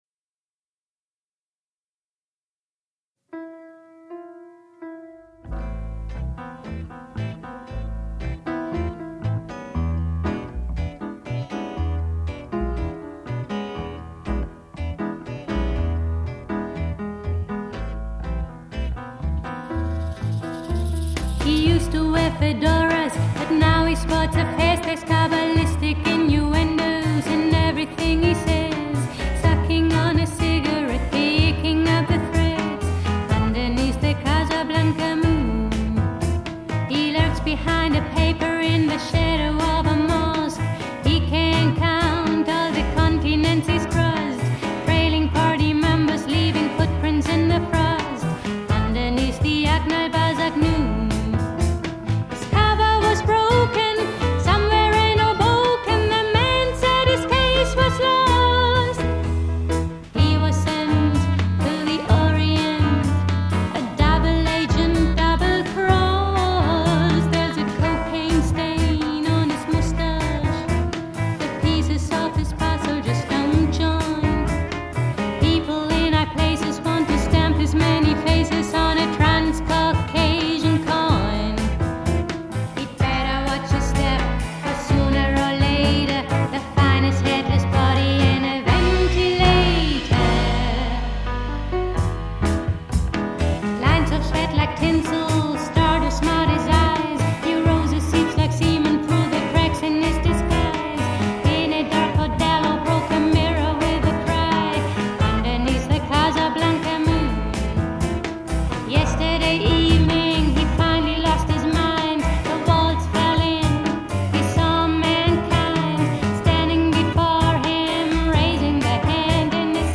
German/English avant-pop group